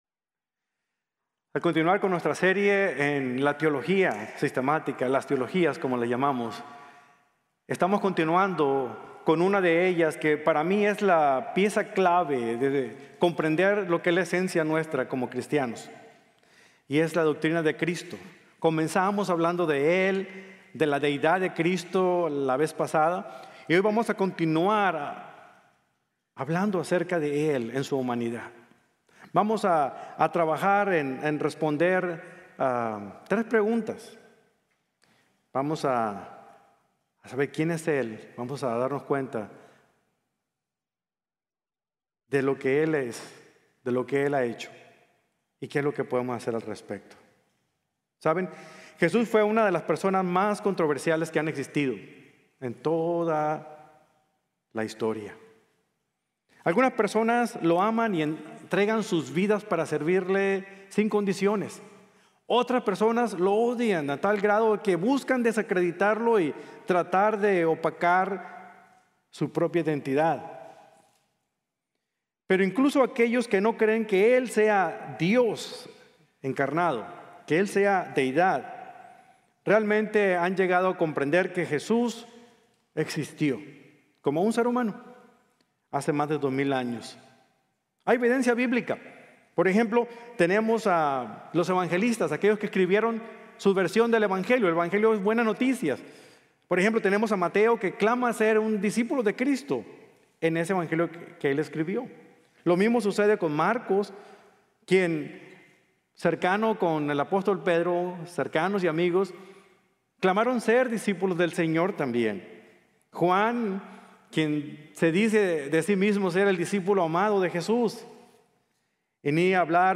CRISTOLOGÍA: Dios Encarnado | Sermon | Grace Bible Church